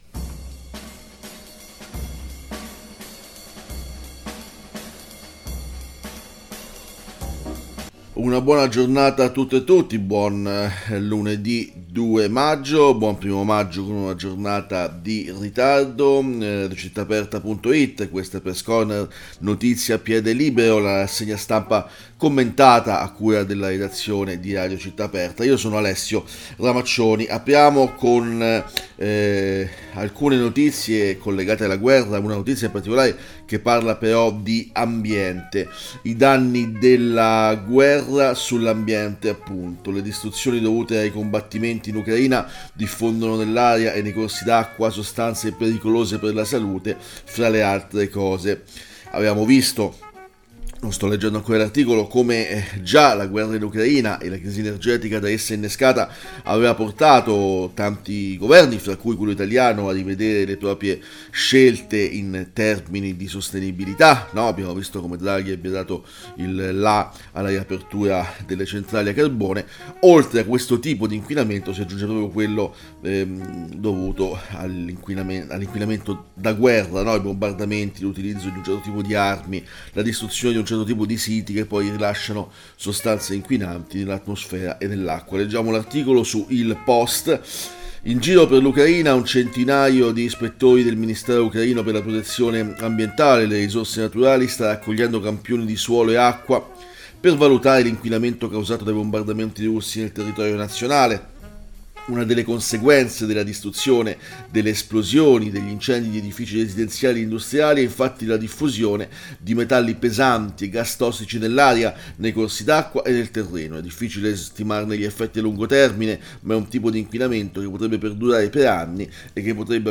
Leggiamo e commentiamo insieme articoli di approfondimento apparsi su quotidiani, periodici, blog, siti specializzati su tutti i temi di interesse del momento.